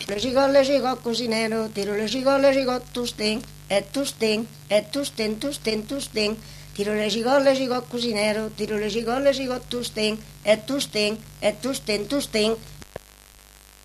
Aire culturelle : Savès
Genre : chant
Effectif : 1
Type de voix : voix de femme
Production du son : chanté
Danse : rondeau